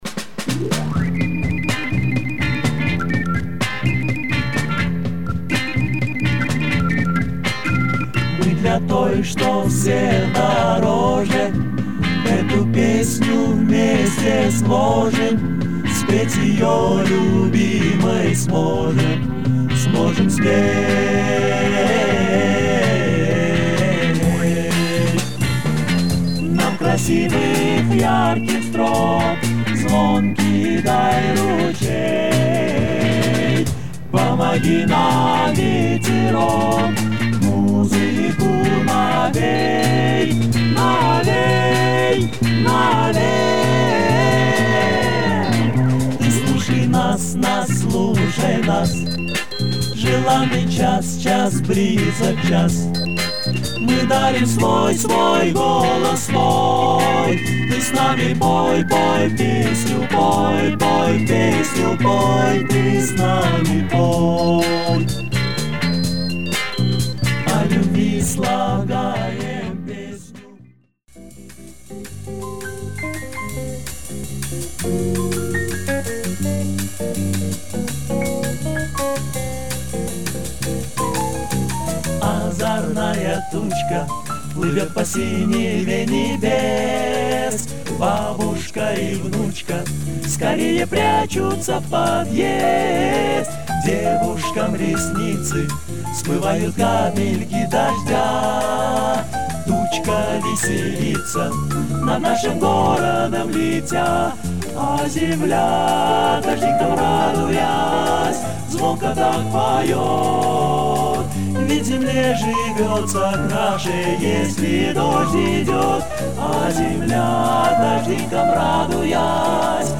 vocal jazz band